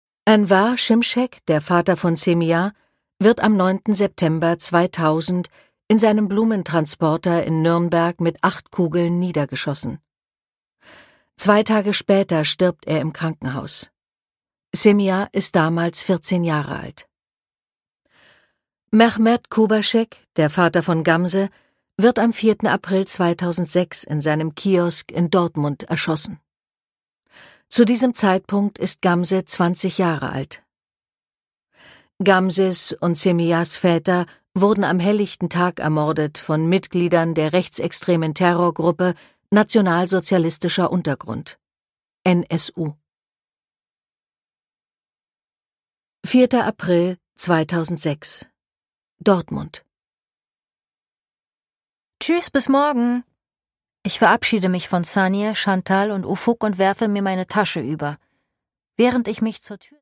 Produkttyp: Hörbuch-Download
Erzählendes Sachhörbuch ab 14 Jahren über die Morde des NSU, authentisch und emotional erzählt.